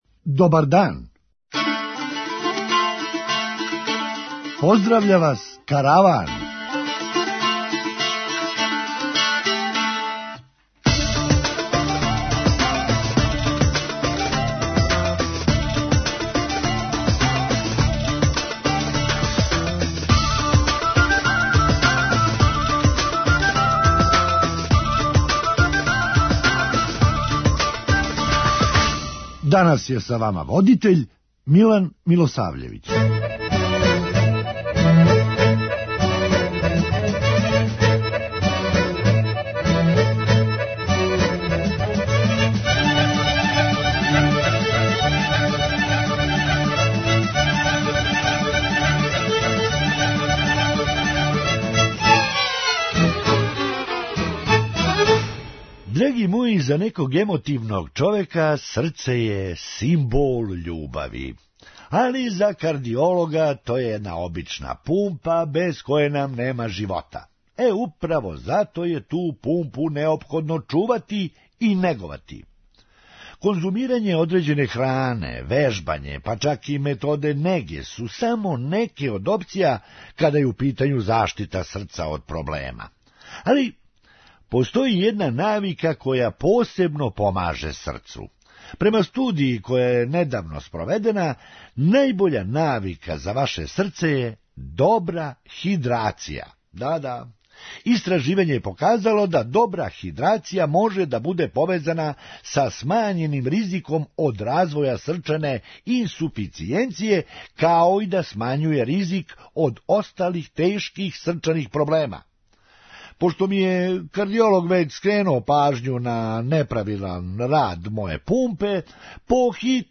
Хумористичка емисија